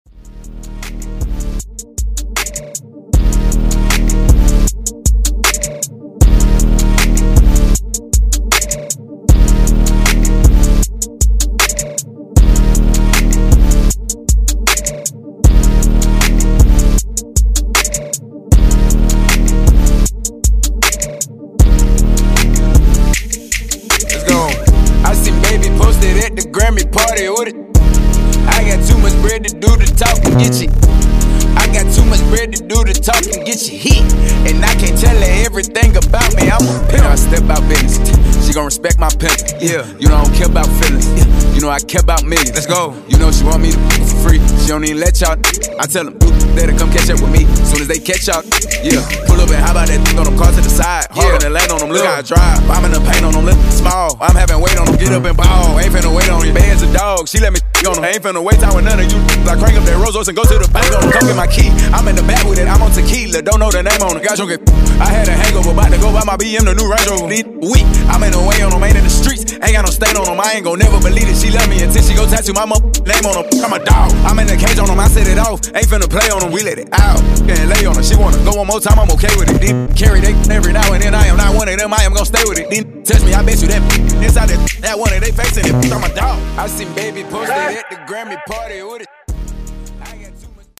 Genres: HIPHOP , R & B , RE-DRUM Version: Clean BPM: 78 Time